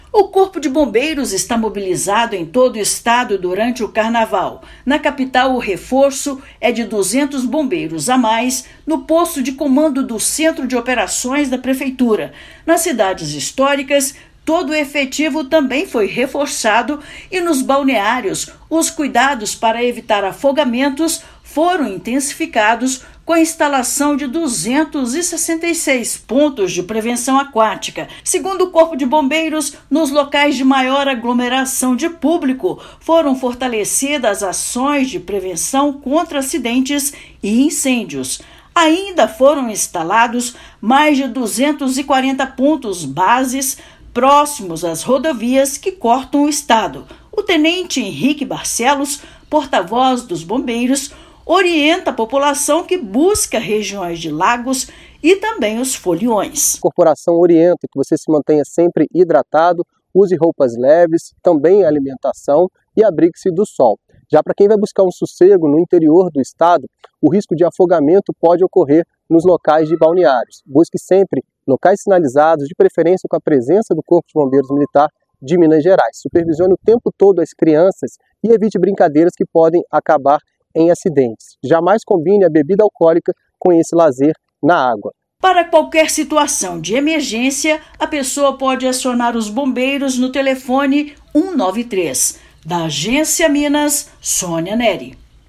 [RÁDIO] Bombeiros planejam reduzir o tempo resposta com mais pontos de reforço no Carnaval em Minas
Todo o efetivo estará empenhado na prevenção de afogamentos, além de estradas e aglomeração de público. Ouça matéria de rádio.